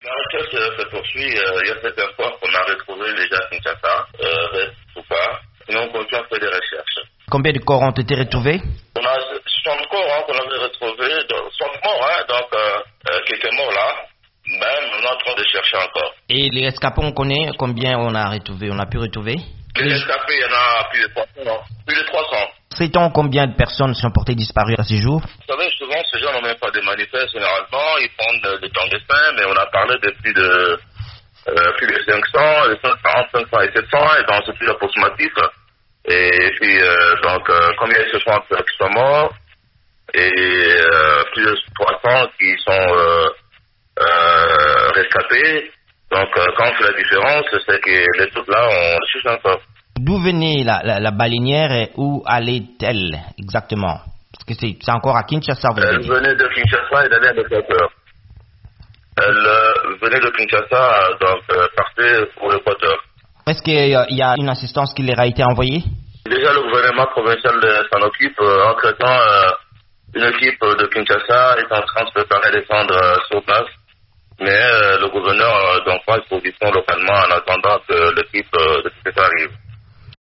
Des recherches se poursuivent après le naufrage d'une embarcation appelée "baleinière" la nuit du dimanche à lundi près de Kinshasa. VOA Afrique a joint le ministre des Affaires humanitaires de la RDC, Steve Mbikayi.